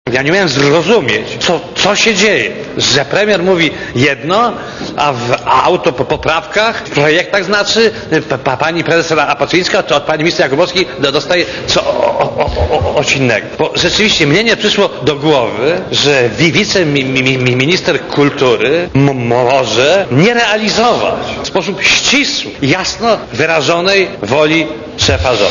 Nauki wyniesione z krytycznej analizy literatury oraz doświadczenia, jak sie wyraził, kryminalisty, każą mi sądzić, że była wiceminister kultury Aleksandra Jakubowska mija się z prawdą marginalizując rolę sekretarza KRRiTV, Włodzimierza Czarzastego w sprawie - powiedział Adam Michnik przed sejmową komisją śledczą badającą sprawę Rywina.
Mówi Adam Michnik (98 KB)